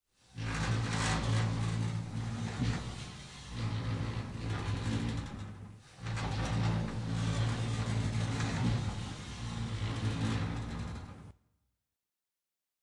DOORS » autoslidingdoors01
描述：Remix of autoslidingdoors.aiff using noise reduction and compression to make a cleaner more detailed sound.
标签： sliding automatic interior automated doors glass
声道立体声